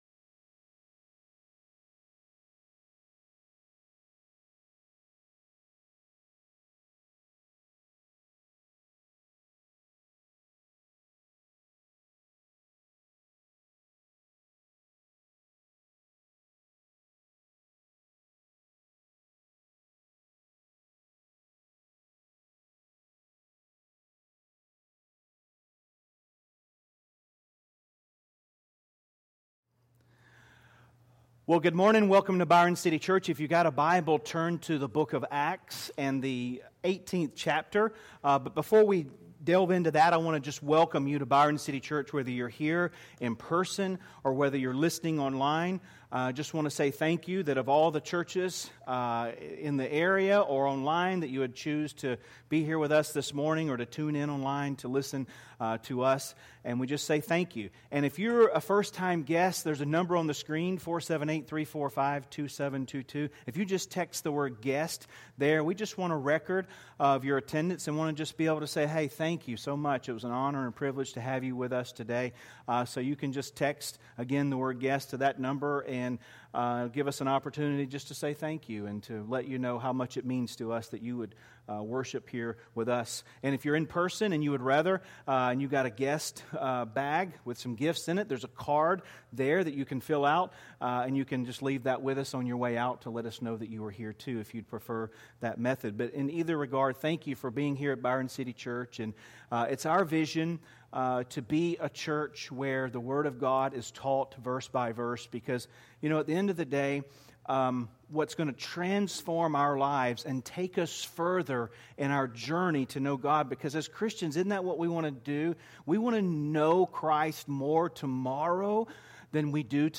Sermons | Byron City Church